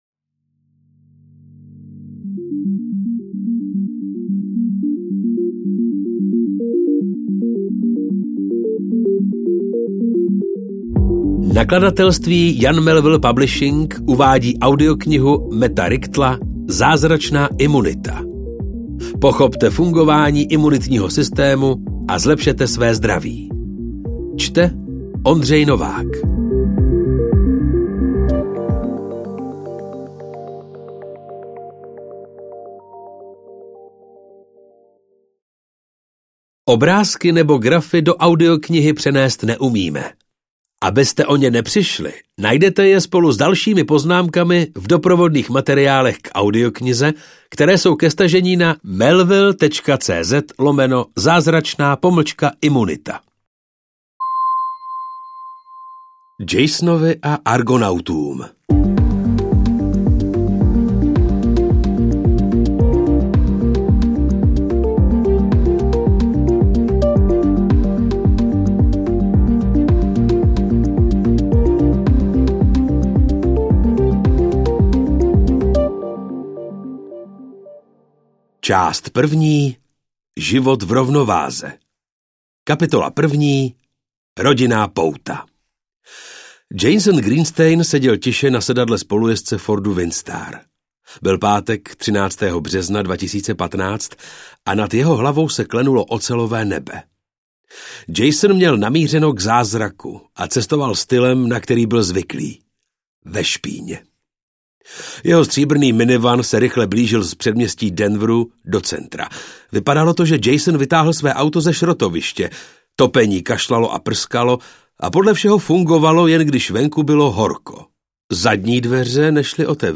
Poslechněte si skvěle vyprávěný a oduševněle zpracovaný průzkum o lidském imunitním systému – klíč ke zdraví a pohodě, životu a smrti. Audiokniha spojuje vědecké objevy s osobními příběhy čtyř životů.
audiokniha